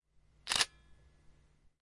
camera shutter fast
描述：camera shutter operating at fast pace
标签： fast camera shutter
声道立体声